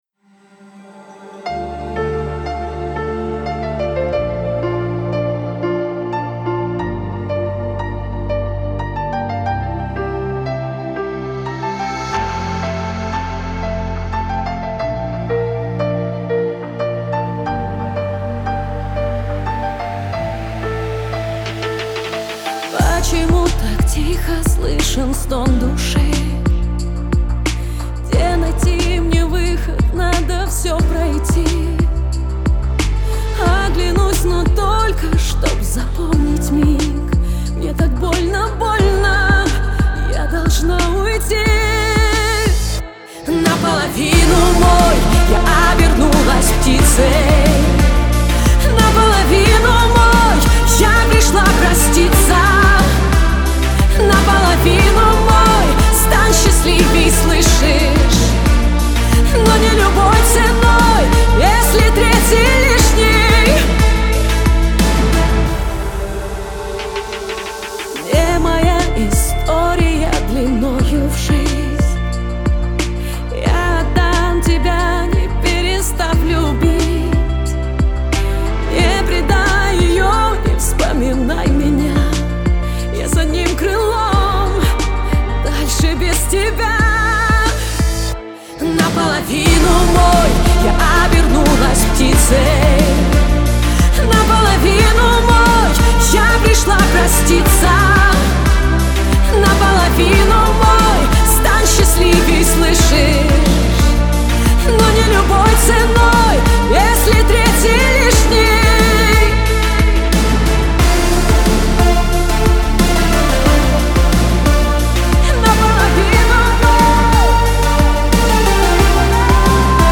это яркий пример поп-баллады